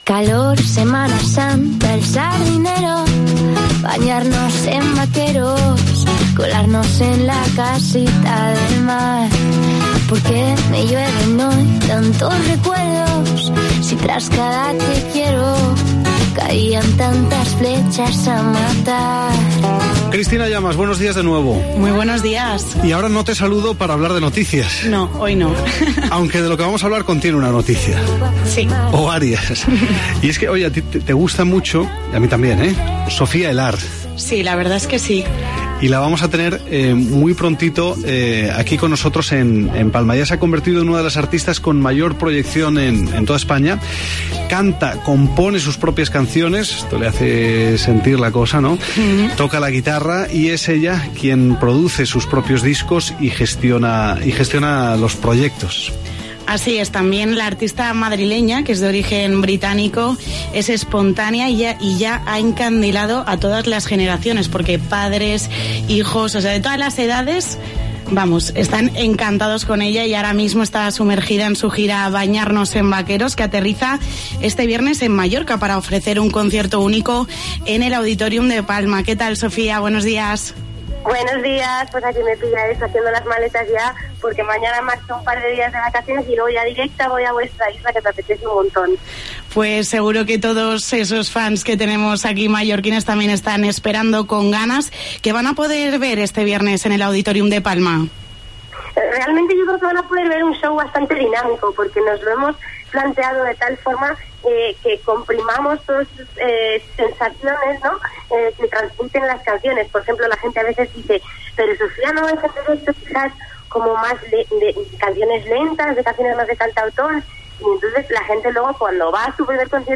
El pasado lunes pudimos hablar con Sofía, con motivo de la gira 'Bañarnos en vaqueros', que la traerá a Mallorca este viernes 23 de agosto , en el Auditorium de Palma.